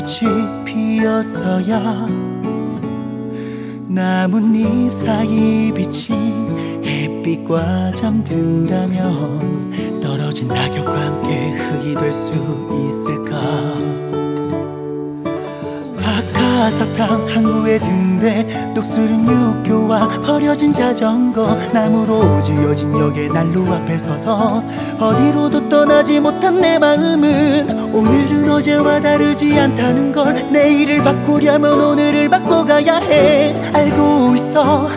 2025-06-26 Жанр: Поп музыка Длительность